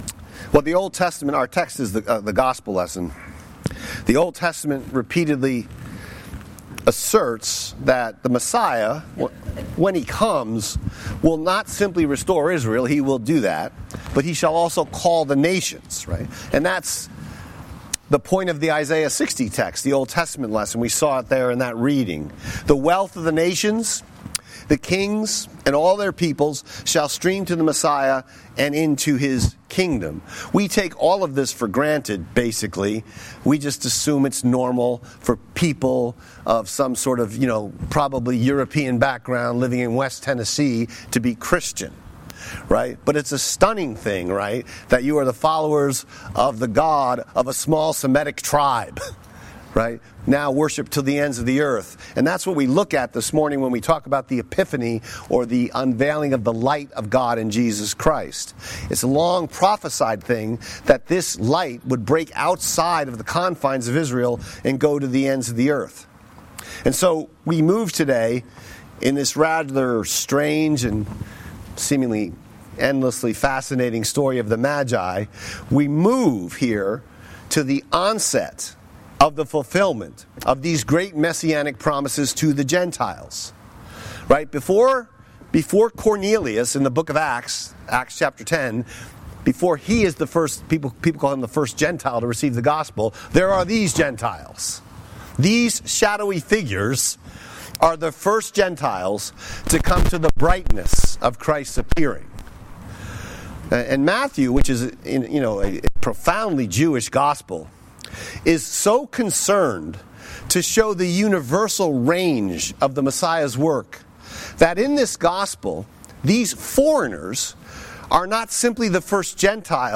Sermon text: Matthew 2:1-12